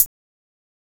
HiHat (21).wav